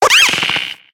Grito de Blitzle.ogg
Grito_de_Blitzle.ogg